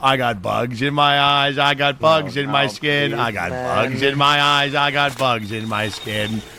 bugs in my eyes Meme Sound Effect
Category: Games Soundboard